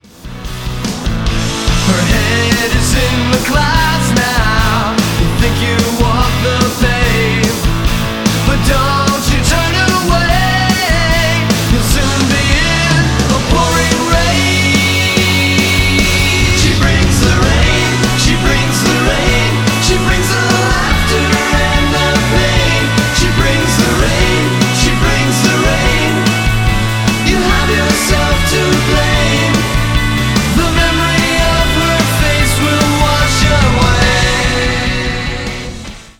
German single remix